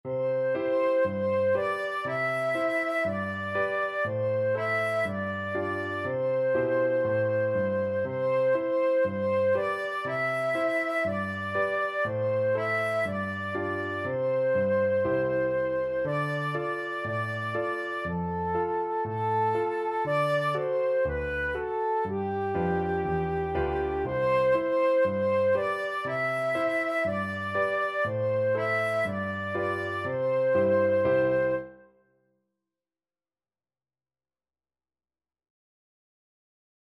Flute
Allegro (View more music marked Allegro)
C major (Sounding Pitch) (View more C major Music for Flute )
4/4 (View more 4/4 Music)
G5-E6
Traditional (View more Traditional Flute Music)